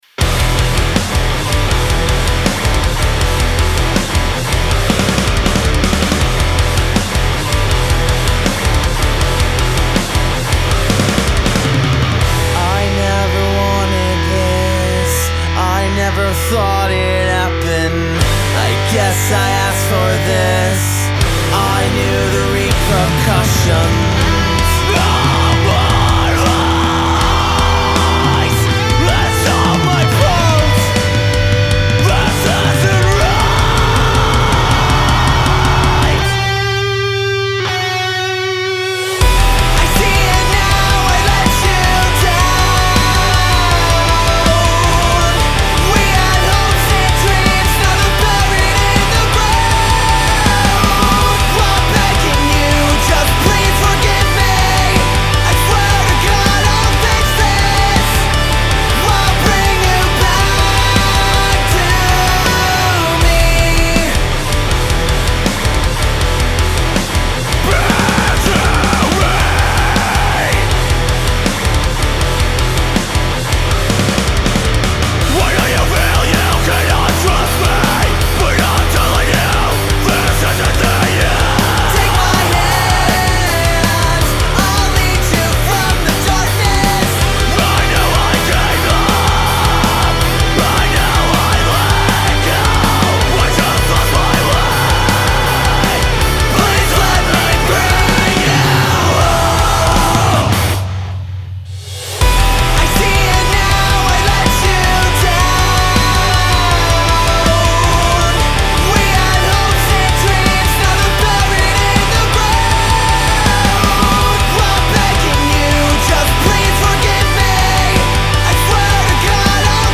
Metal Before (Coming Soon)